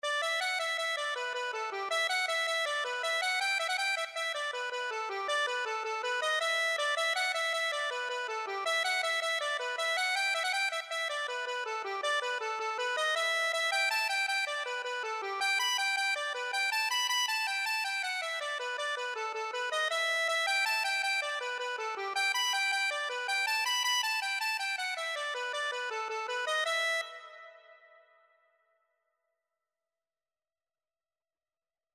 Free Sheet music for Accordion
9/8 (View more 9/8 Music)
E minor (Sounding Pitch) (View more E minor Music for Accordion )
Accordion  (View more Easy Accordion Music)
Traditional (View more Traditional Accordion Music)